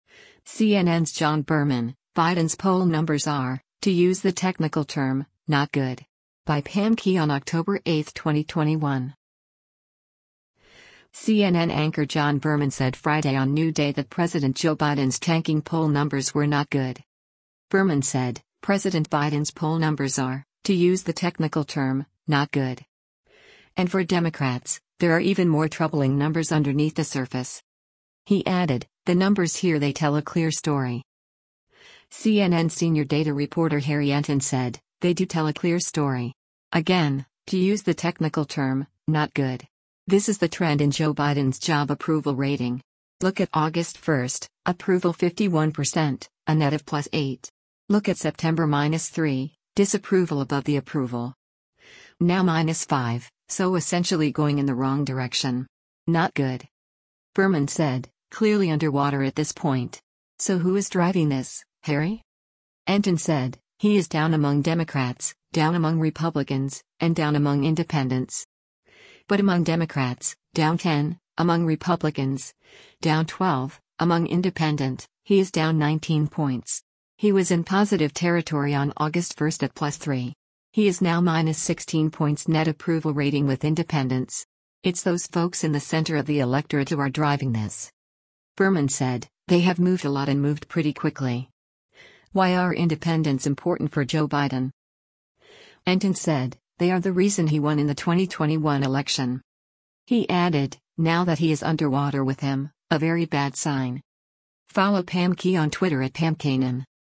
CNN anchor John Berman said Friday on “New Day” that President Joe Biden’s tanking poll numbers were “not good.”